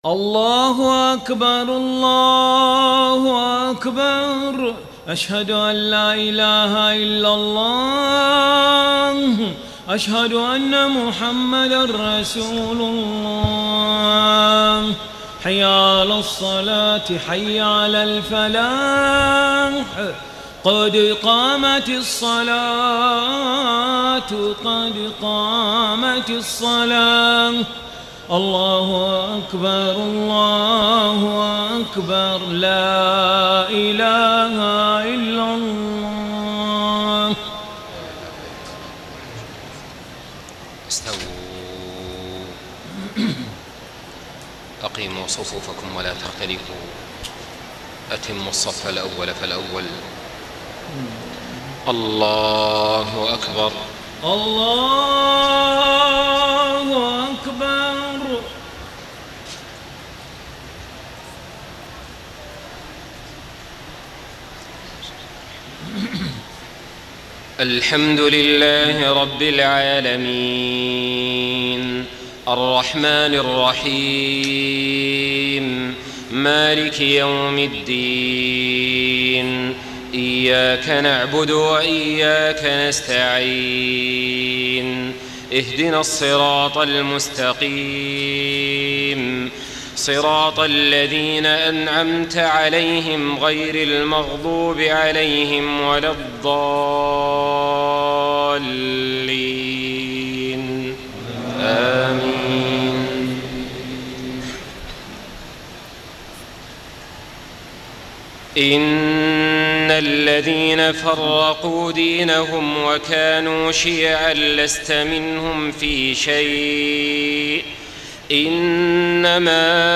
صلاة العشاء 2-9-1434 من سورة الانعام > 1434 🕋 > الفروض - تلاوات الحرمين